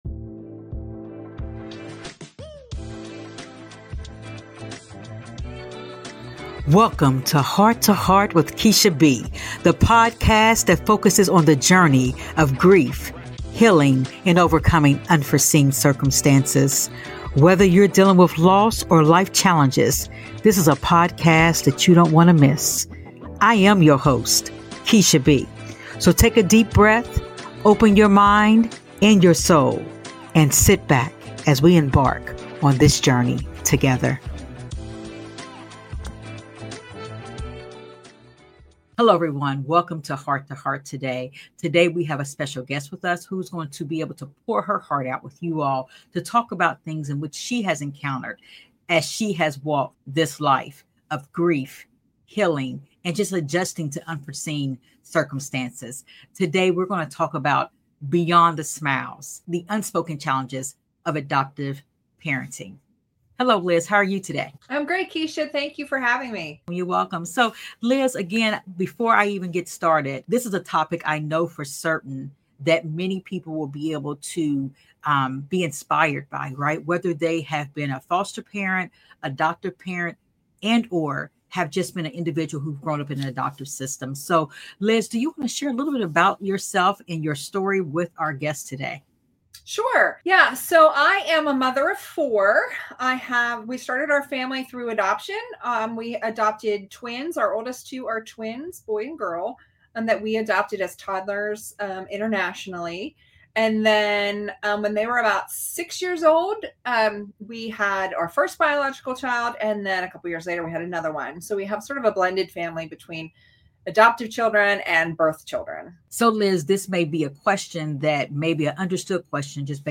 In this deeply personal and eye-opening episode, we sit down with a courageous adoptive mother who shares the raw and unfiltered truth about her journey of international adoption.